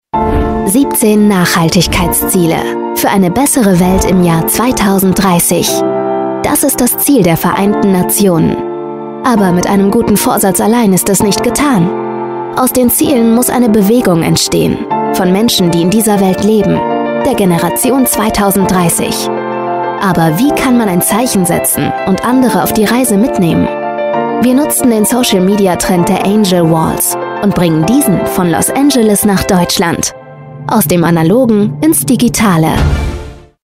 Junge & frische Werbesprecher:innen – New Voices
junge Profisprecherin mit Persönlichkeit
Stimmcharakter:         cool, natürlich, markant, positiv